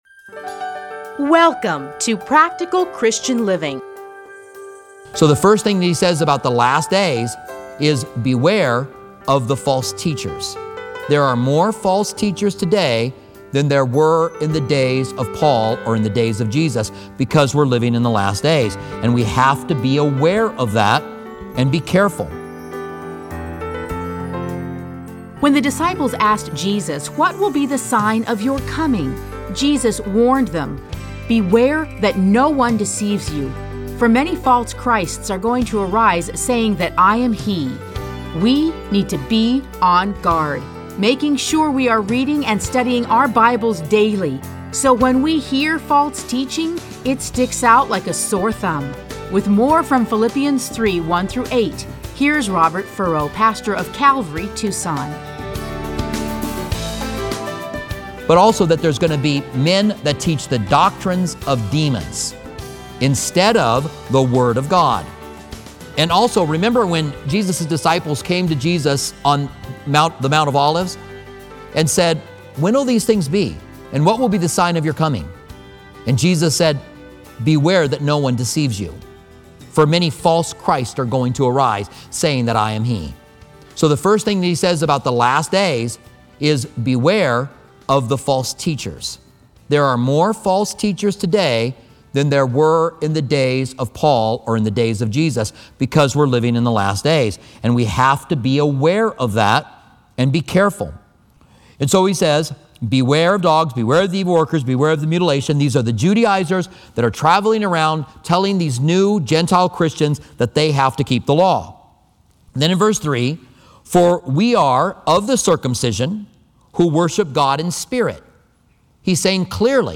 Listen to a teaching from A Study in Philippians 3:1-8.